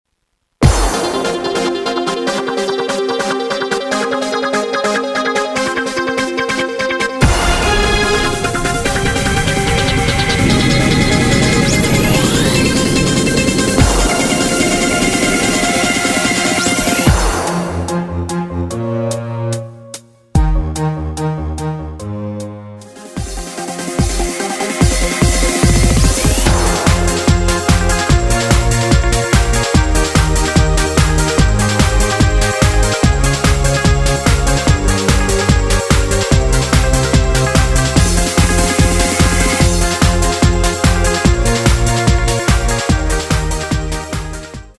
바일올린 연주용 MR 입니다. 키 Fm 가수
원곡의 보컬 목소리를 MR에 약하게 넣어서 제작한 MR이며